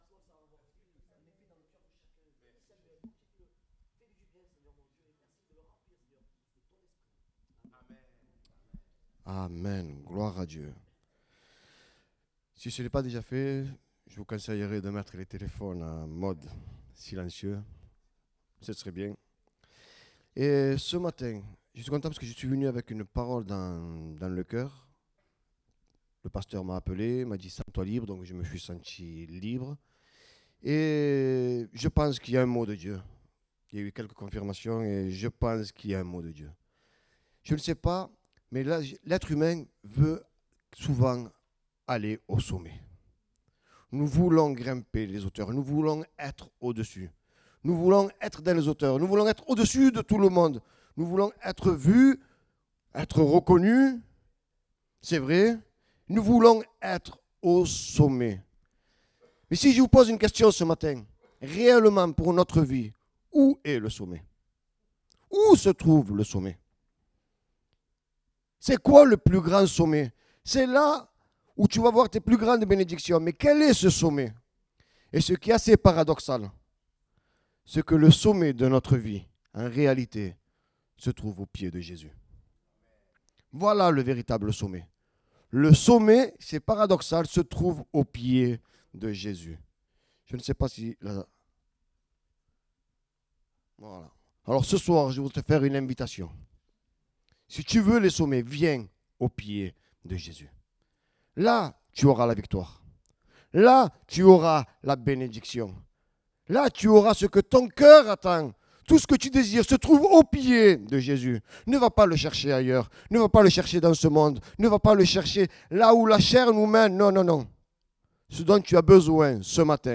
Date : 29 septembre 2024 (Culte Dominical)